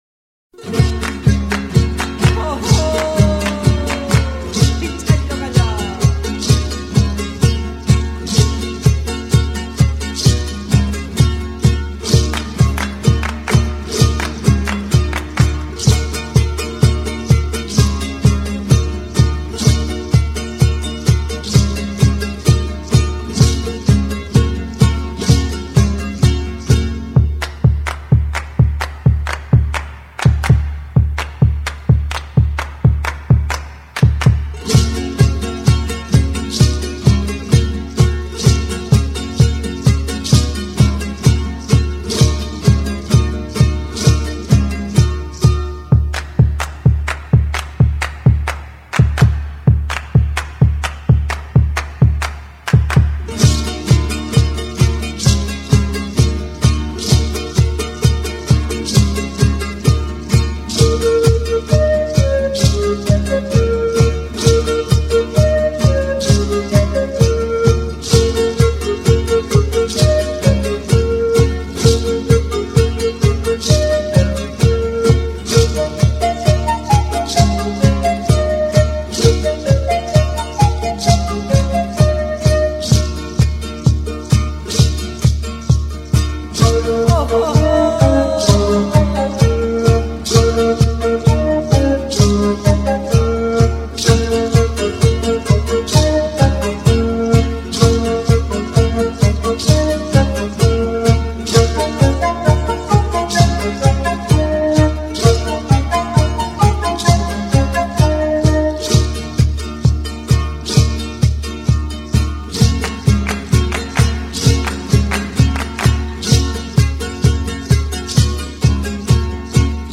Жанр: Ethnic